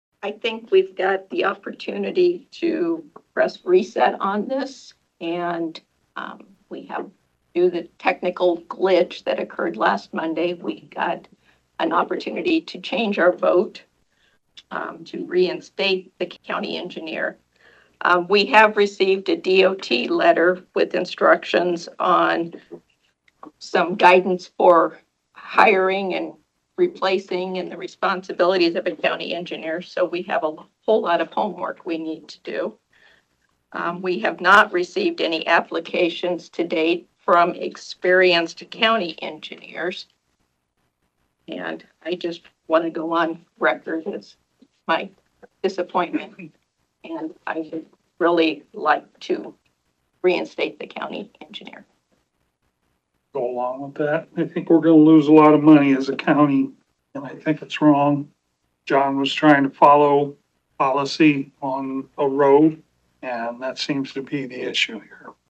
When back in open session, a motion was made by Supervisor Tim Wichman to deny the grievance from County Engineer John Rasmussen.
The following comments were made by Supervisors Susan Miller and Brian Shea.